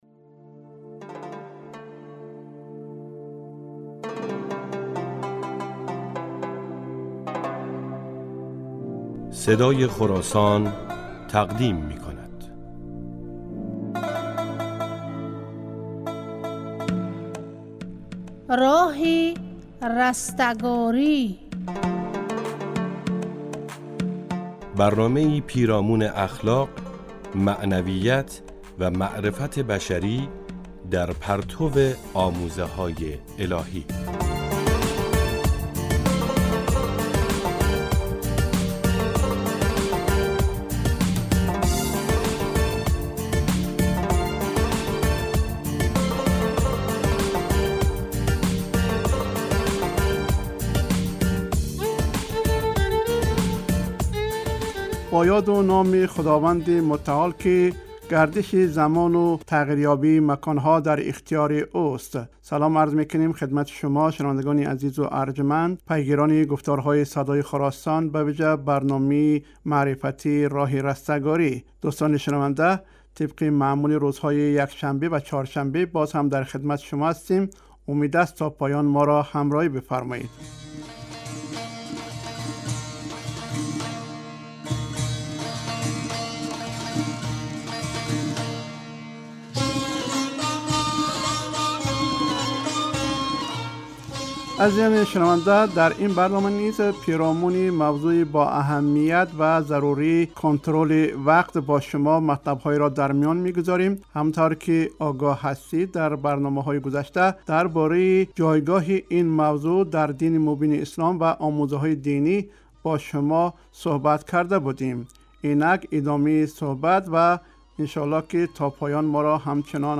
Дар барномаи "Роҳи растагорӣ" талош мекунем, дар бораи ҷанбаҳои мухталифи эътиқодӣ, ибодӣ, ахлоқӣ, иқтисодӣ ва иҷтимоии ҷомеаи башарӣ дар партави омӯзаҳои илоҳӣ матлабҳоеро барои шумо шунавандагони азизи "Садои Хуросон" баён кунем. Таҳия кунанда ва гӯянда